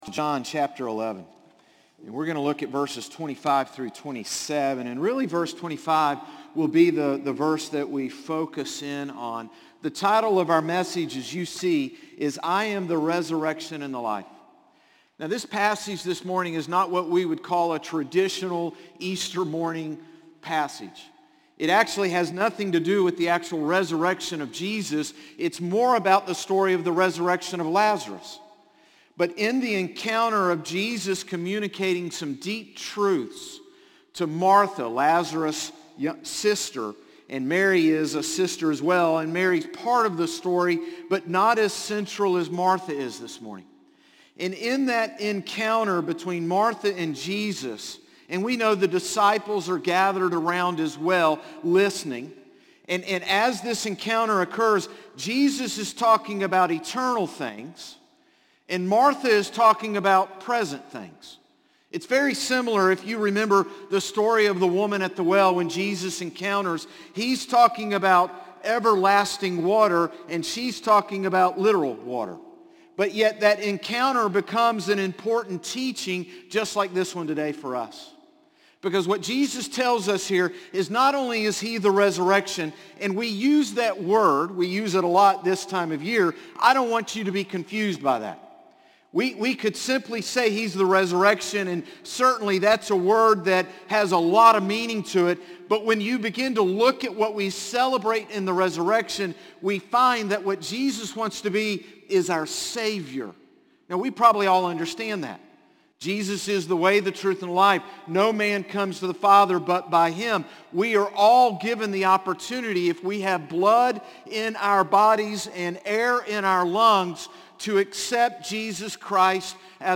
Sermons - Concord Baptist Church